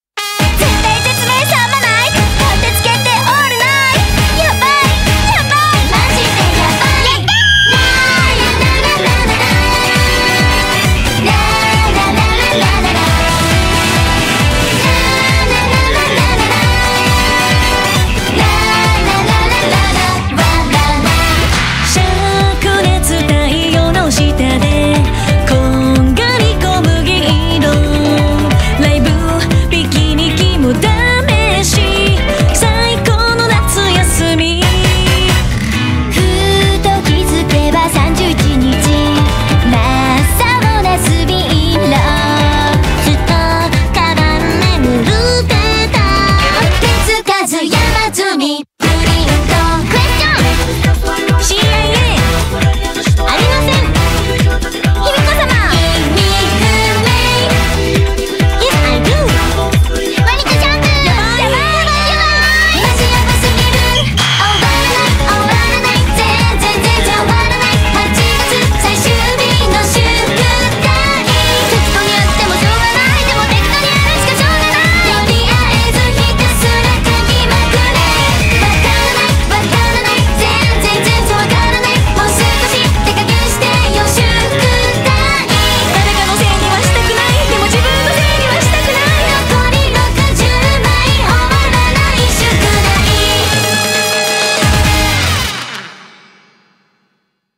BPM135
Audio QualityPerfect (Low Quality)